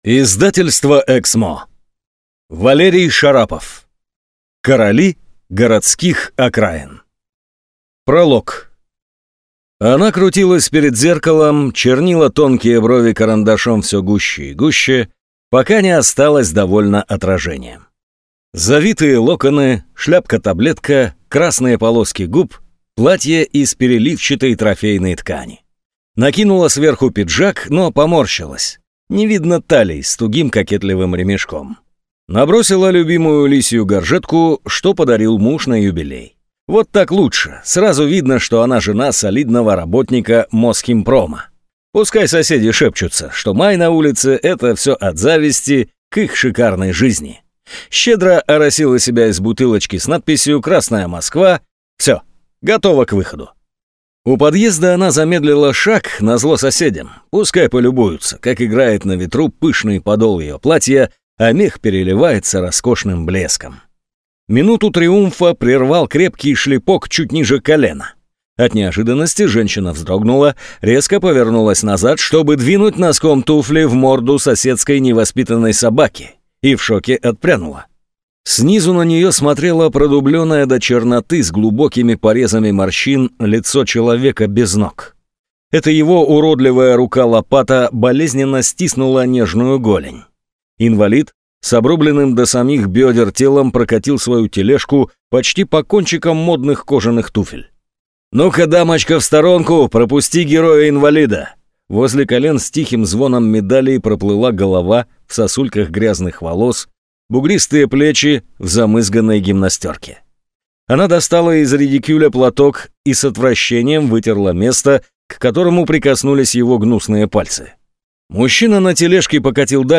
Аудиокнига Короли городских окраин | Библиотека аудиокниг